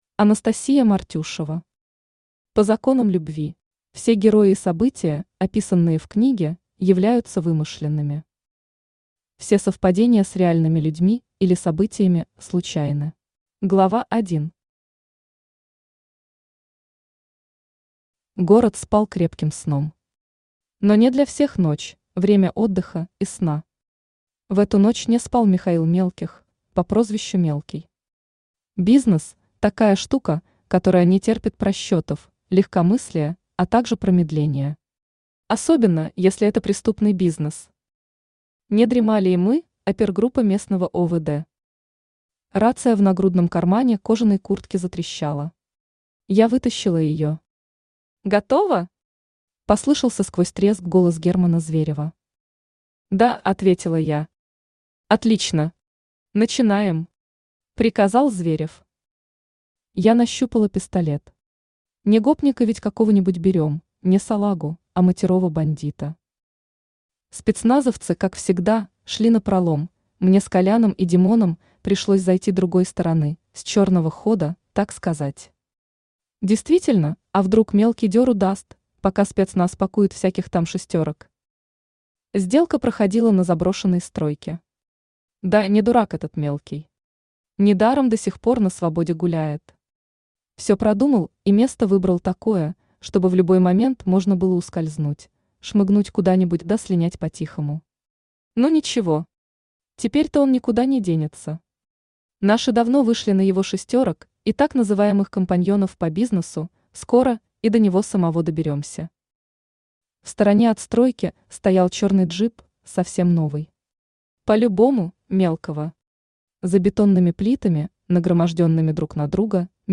Аудиокнига По законам Любви | Библиотека аудиокниг